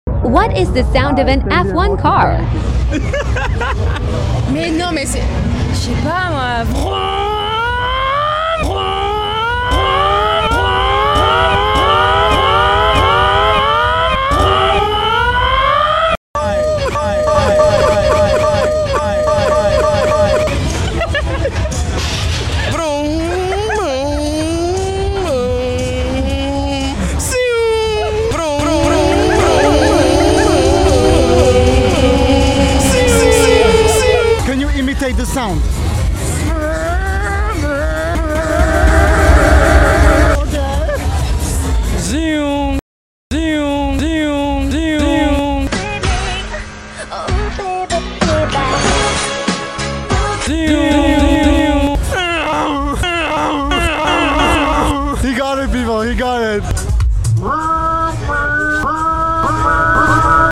She struggled to find the good sound but she deserves a round of applause for the energy she gave😂